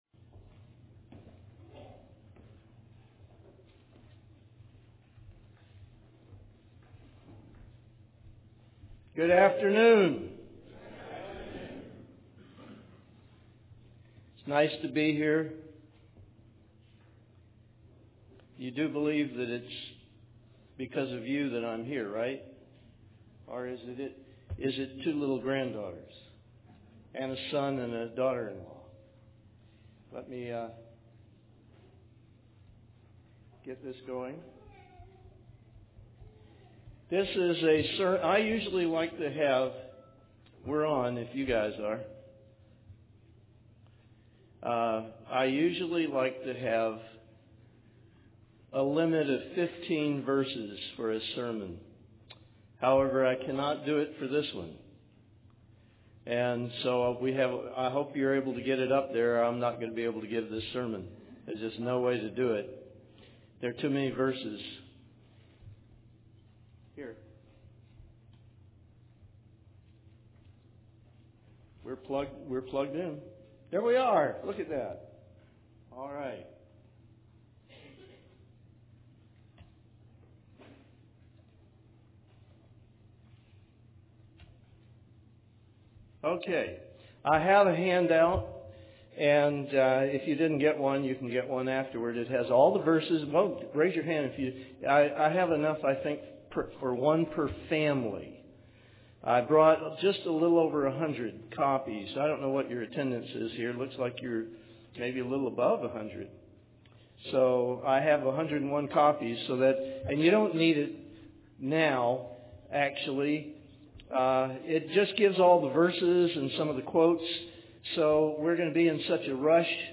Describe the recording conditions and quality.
Given in Dallas, TX Knoxville, TN